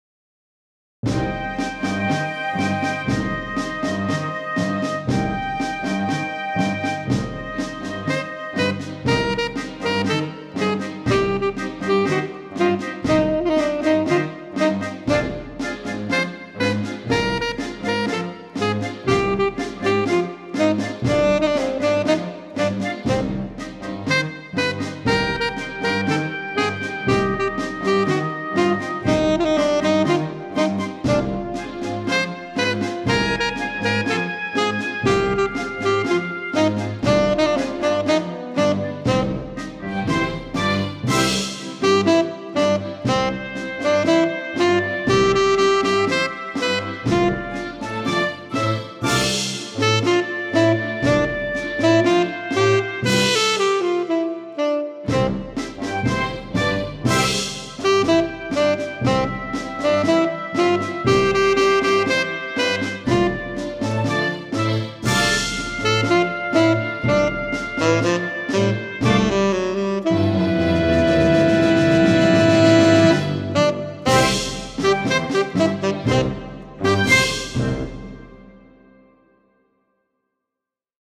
full orchestral backing tracks
Classical Music